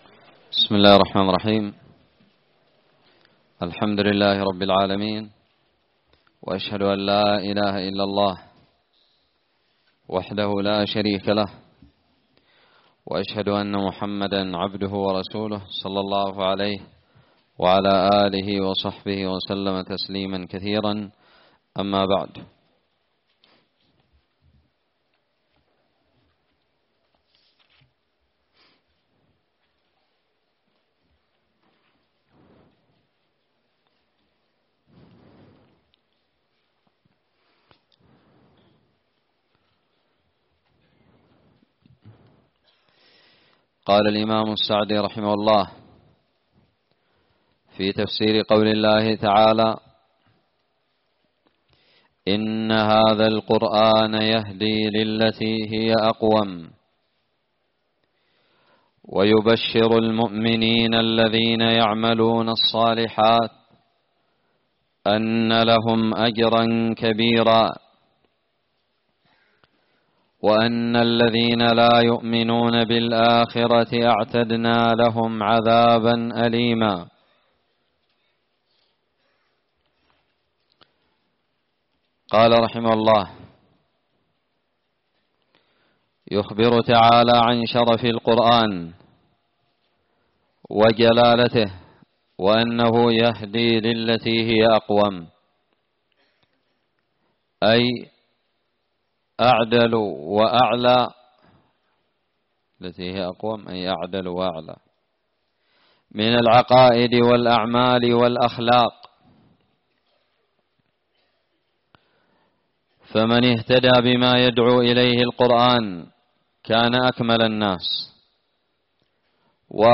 الدرس الثالث من تفسير سورة الإسراء
ألقيت بدار الحديث السلفية للعلوم الشرعية بالضالع